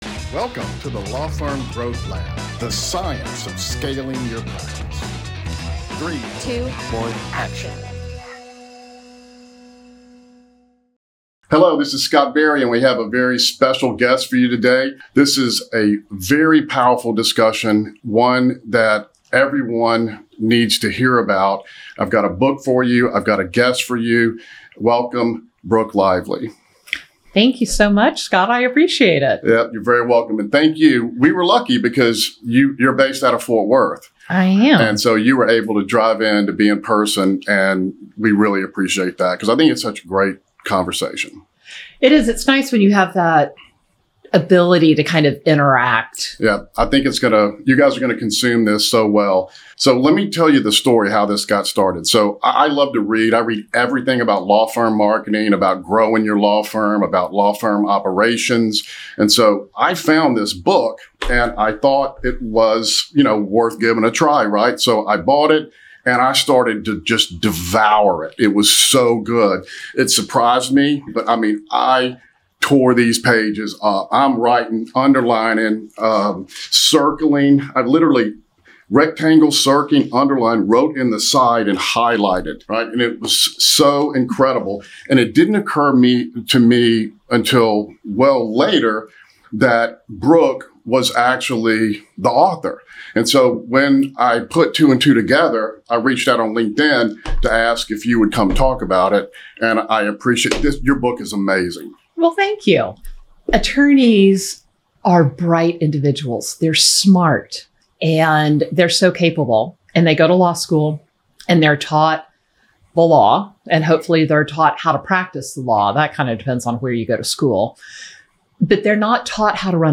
If you have ever felt financial stress, uncertainty about cash flow, or that constant feeling that you are missing something inside your firm, this conversation will give you clarity.